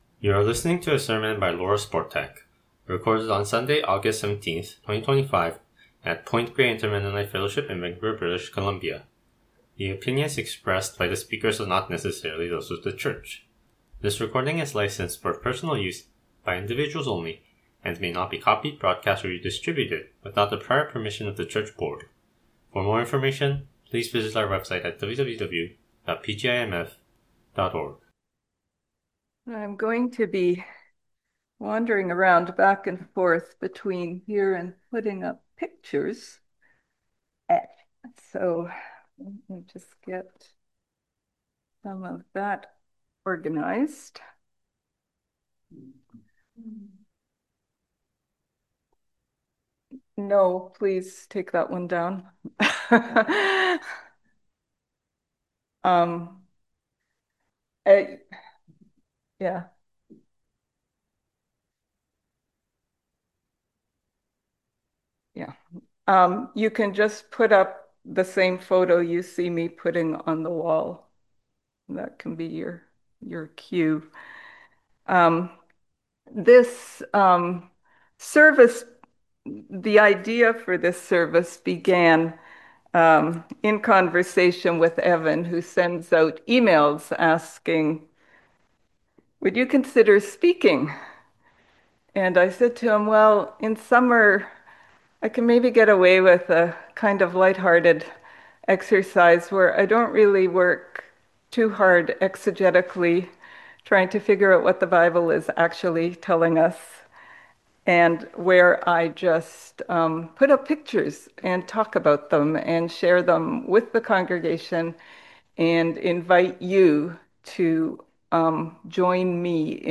Sermon Recording: Download